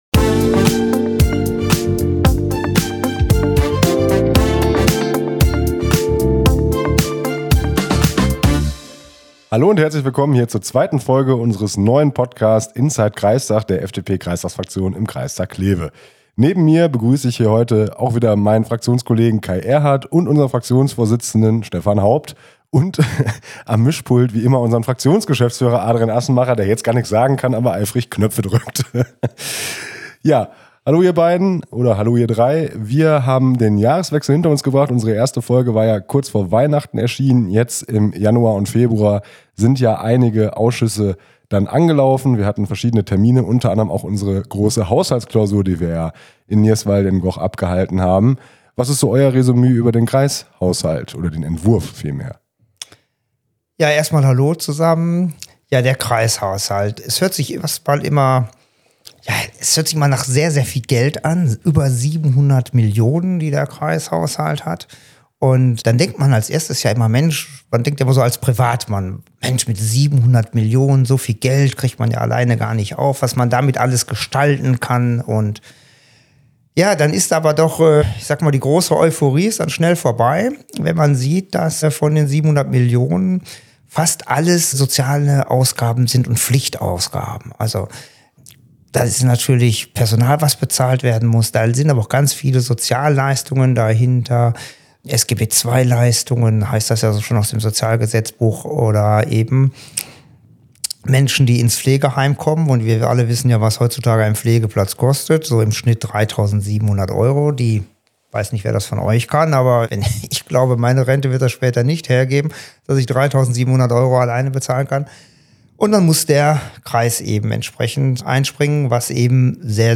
Roman Straub, Stephan Haupt und Kay Ehrhardt begrüßen euch zur zweiten Folge von "Inside Kreistag" und tauchen tief in den Kreishaushalt 2026, Taxitarife, RE10-Probleme und Kita-Herausforderungen im Kreis Kleve ein.